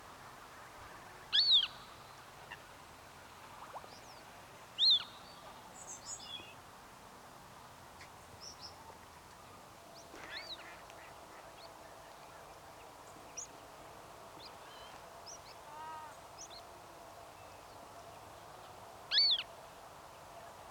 Bruine Kiekendief